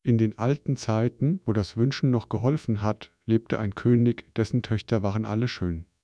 A free to use, offline working, high quality german TTS voice should be available for every project without any license struggling.
sample05-ForwardTacotron-HifiGAN.wav